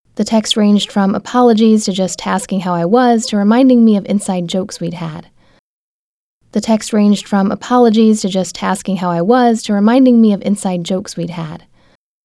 Audio file suddenly sounds like I got a squeaky toy...
Coming back to it, I started to notice an occasional odd squeaky sound, almost like a dog toy, under voice - it never occurs unless I’m speaking.
Unfortunately its not a hallucination caused by sleep-deprivation, I can hear it too : it’s like clarinet, only when volume is loud. I don’t think you did that in processing, it’s more like a physical resonance-effect on the original recording : the microphone / mic-stand / pop-shield vibrating at a particular frequency driven by the sibilant & plosive energy.